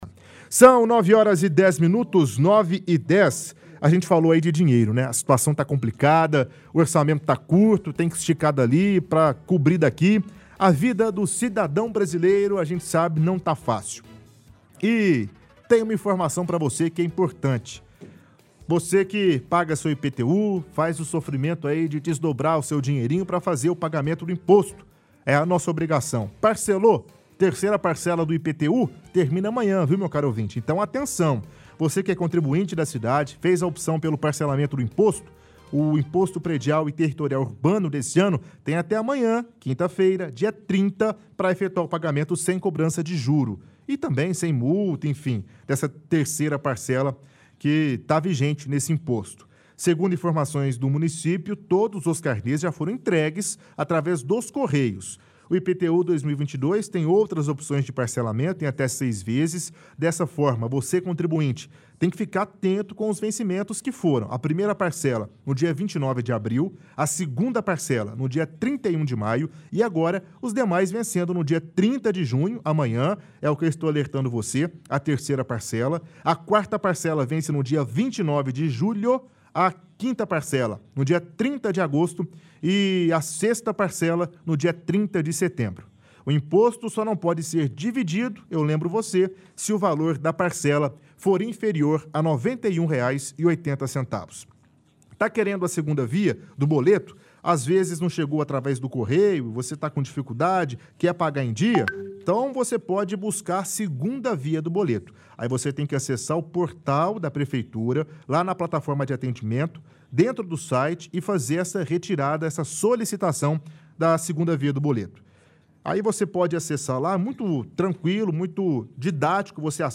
– Apresentador lê trecho de reportagem sobre vencimento do IPTU.